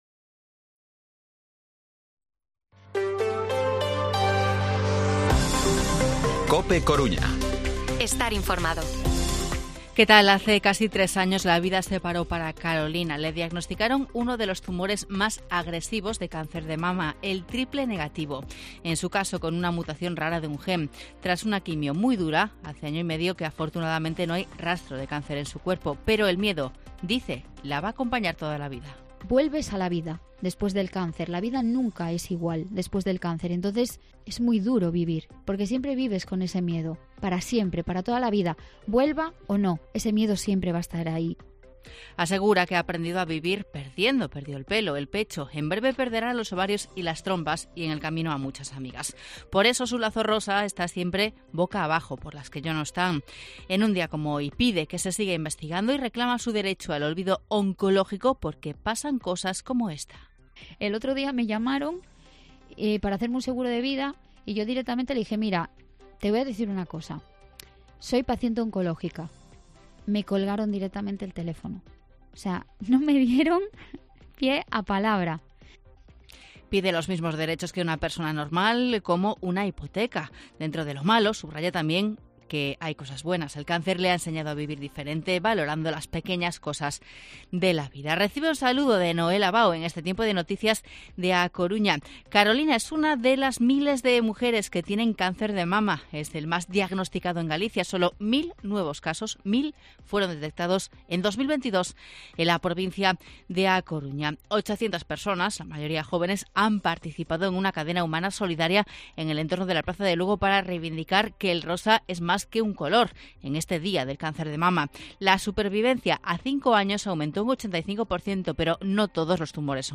informativo Mediodía COPE Coruña jueves, 19 de octubre de 2023 14:20-14:30